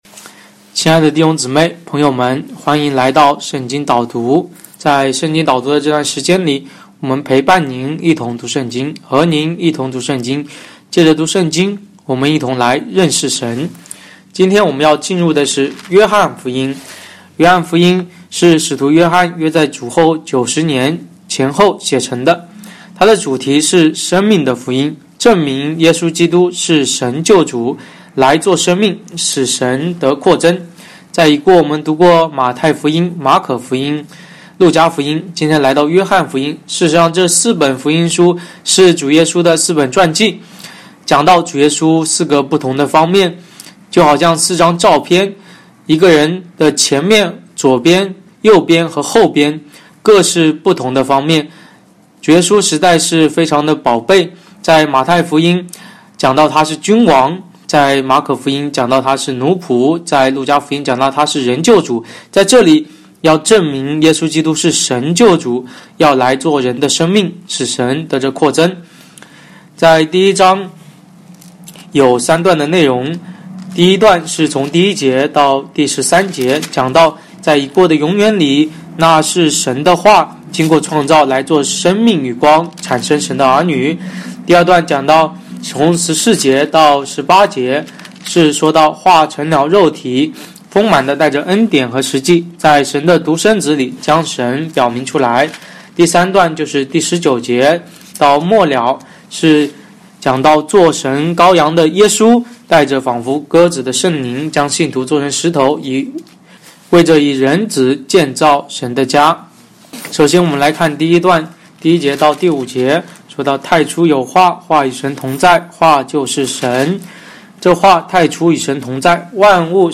约翰福音 第一章 导读音频 週四申言示範 主題：對約翰一章所啟示基督作為神的話之經歷，而過召會的生活 a074讚美主─祂的成肉身 a152主你於我何其豐富 無論我們聽或不聽，那靈都在引導我們，指教我們，改正我們，平衡我們，並向我們說話！